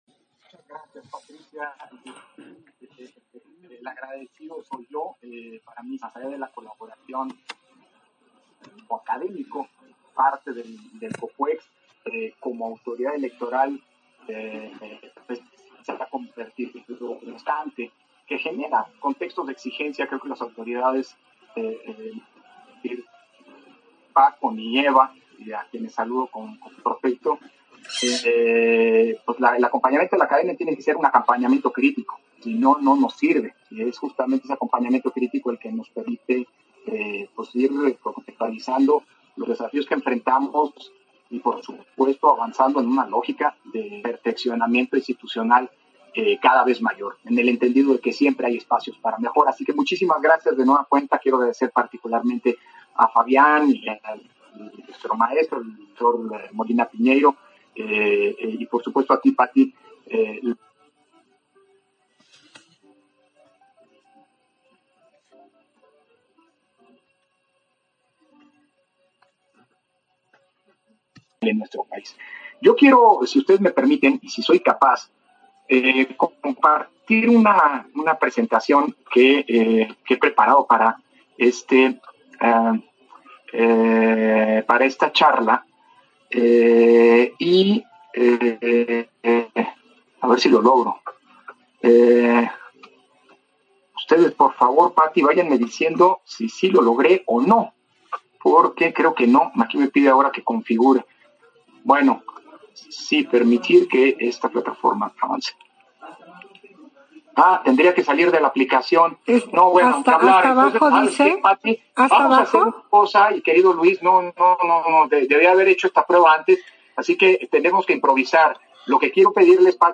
Intervención de Lorenzo Córdova, en la Mesa Redonda, La función de la división de poderes y los Órganos Constitucionales Autónomos en el presidencialismo carismático en México